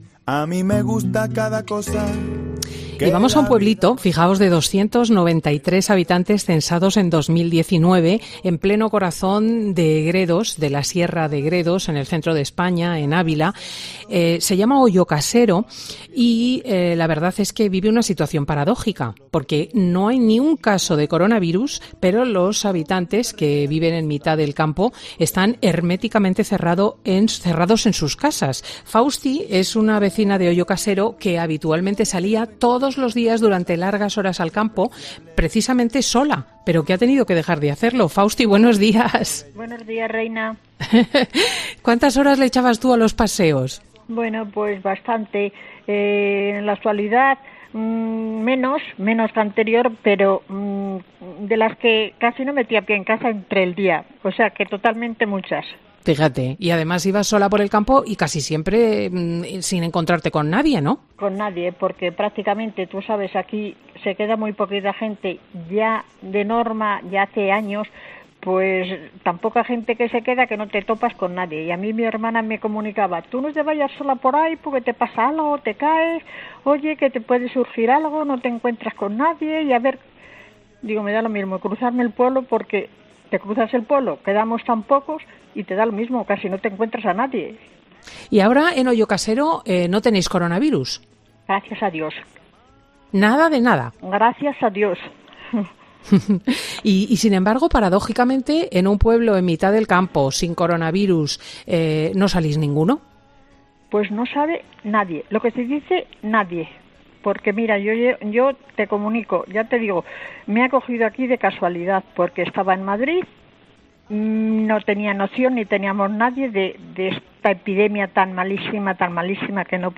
"Fin de Semana" es un programa presentado por Cristina López Schlichting, prestigiosa comunicadora de radio y articulista en prensa, es un magazine que se emite en COPE, los sábados y domingos, de 10.00 a 14.00 horas.